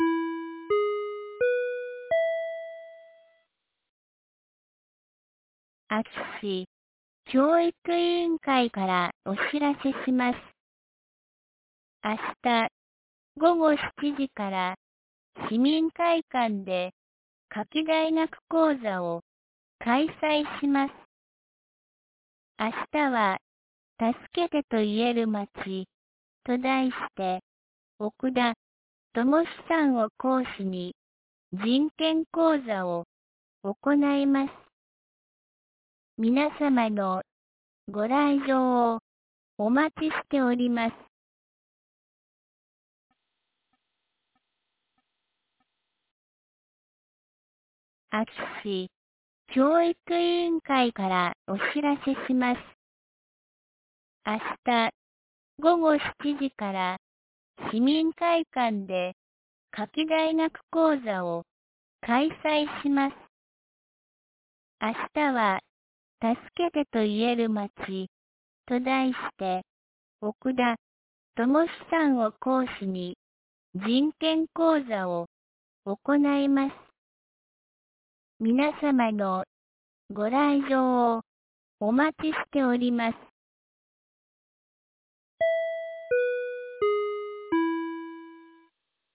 2023年09月06日 17時11分に、安芸市より全地区へ放送がありました。